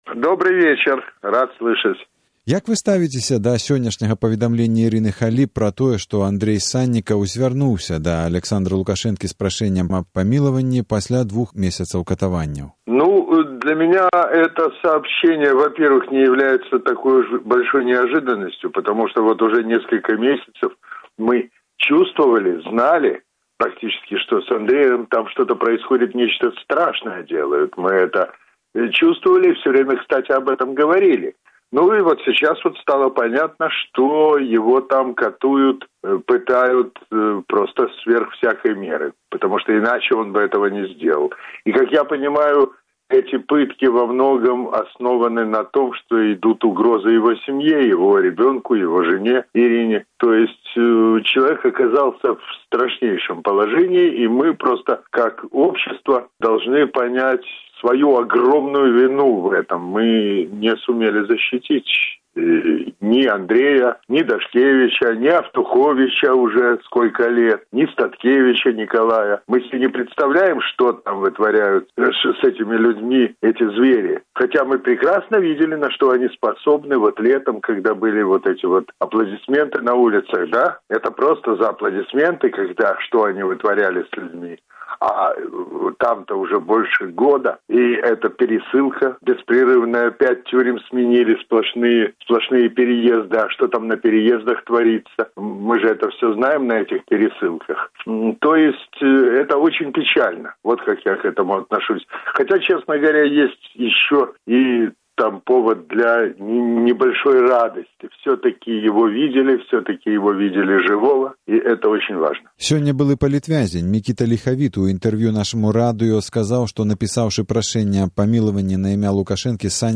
Гутарка зь Юрыем Хашчавацкім, 25 студзеня 2012 году